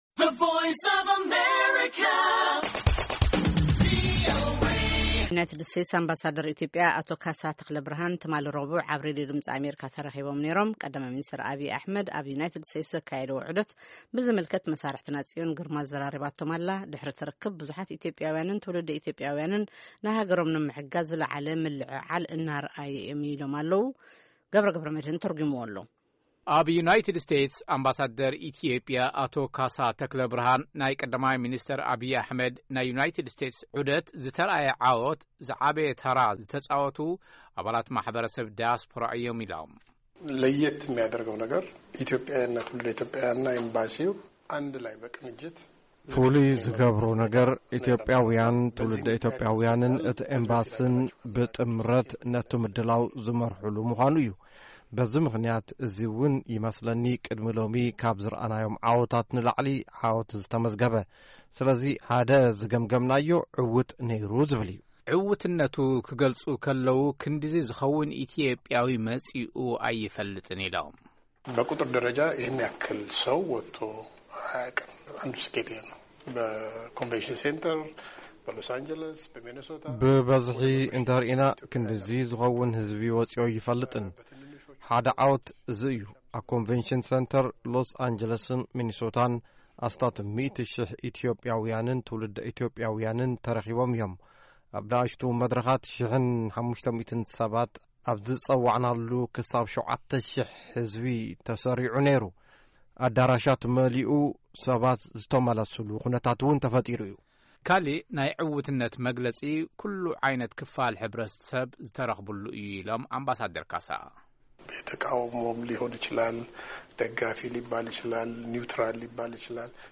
ኣምባሳደር ኢትዮጵያ ኣብ ኣሜሪካ ኣቶ ካሳ ተክለብርሃነ ትማሊ ረቡዕ ኣብ ሬድዮ ድምፂ ኣሜሪካ ተረኺቦም ብዛዕባ ቀዳማይ ሚንስተር ኣብይ ኣሕመድ ኣብ ኣሜሪካ ዘካይድዎ ዑደት ብዝምልከት ቃለ መጠይቕ አካይዶም ኔሮም።ንሶም ድሕሪ እቲ ርክብ ቀ/ሚ አብይ አሕመድ ብዙሓት ኢትዮጵያዊያንን ትውልደ ኢትዮጵያዊያንን ንሃገሮም ንምሕጋዝ ዝለዓለ ምልዕዓል የርእዩ ኣለው ኢሎም።